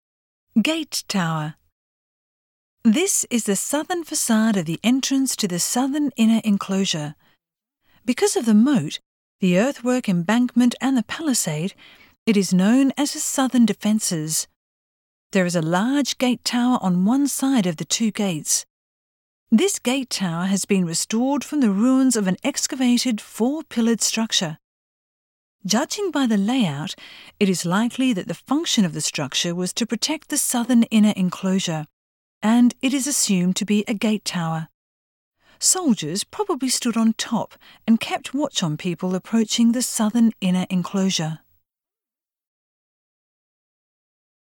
Voice guide